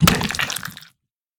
spawner-death-1.ogg